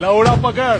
Confused Sanctuary Guardian Sound Effect (Lwda Pakad Meme)
The iconic 'What' sound effect from EarthBound, often associated with the Sanctuary Guardian boss.
confused-sanctuary-guardian-sound-effect-lwda-pakad-meme-2f0facbb.mp3